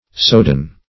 sowdan - definition of sowdan - synonyms, pronunciation, spelling from Free Dictionary Search Result for " sowdan" : The Collaborative International Dictionary of English v.0.48: Sowdan \Sow"dan\, n. [F. soudan.